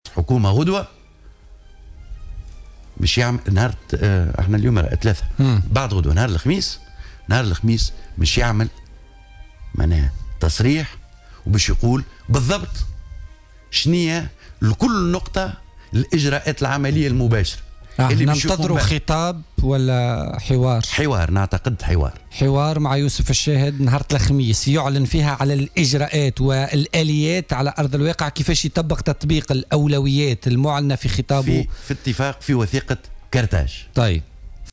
أكد نور الدين بن تيشة، المستشار لدى رئيس الجمهورية في مداخلة له اليوم في برنامج "بوليتيكا" أنه من المنتظر أن يجري رئيس الحكومة يوسف الشاهد الخميس القادم حوارا خاصا على احدى القنوات التلفزية وذلك للإعلان عن اليات وسبل تنفيذ ما جاء في وثيقة قرطاج.